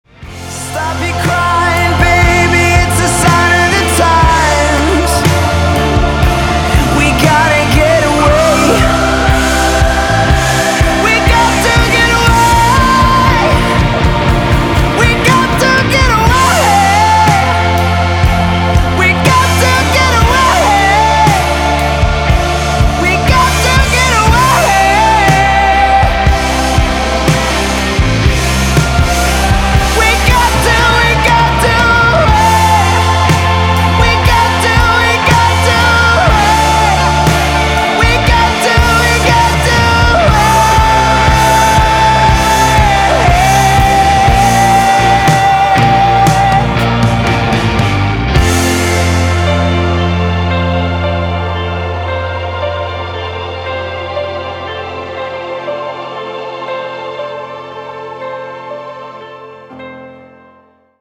• Качество: 320, Stereo
поп
мужской вокал
спокойные